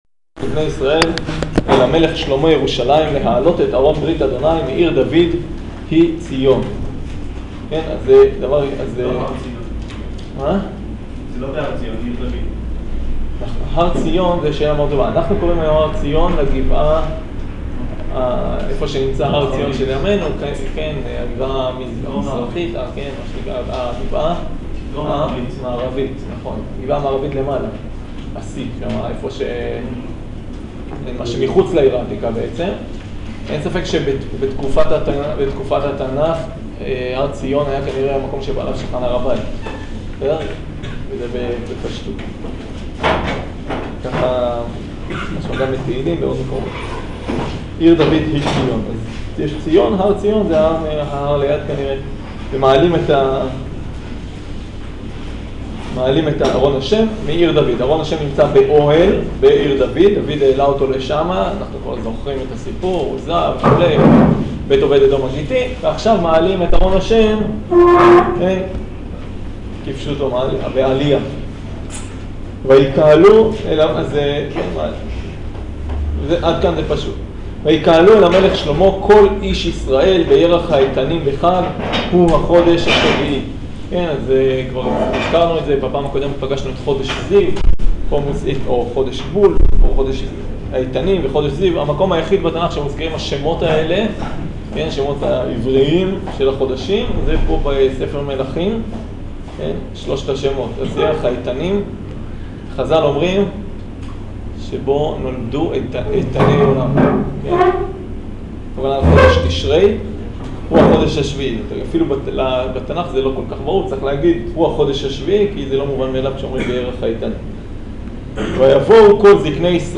שיעור מספר 13 בסדרה, חסר תאריך בשם הקובץ